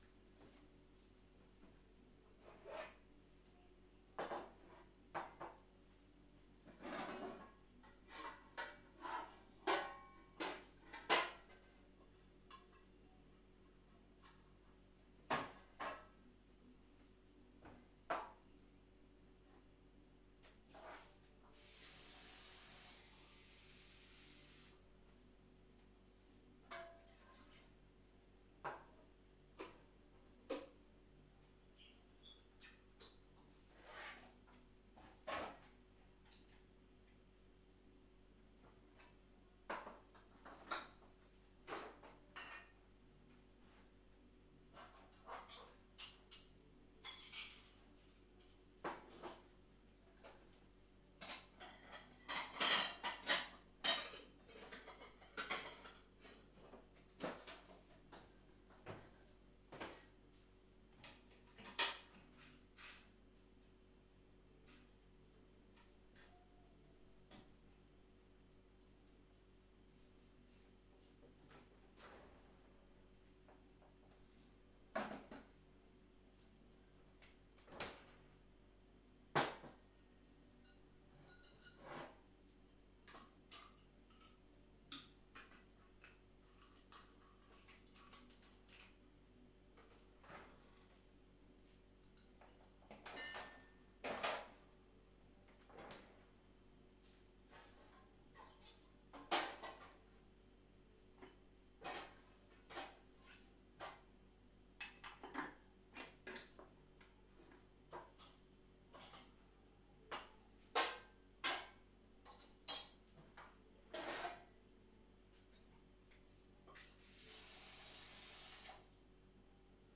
描述：将盘子放在金属架子上的人用Zoom H6录制
标签： 厨房 机架 海绵 点击 金属 餐具
声道立体声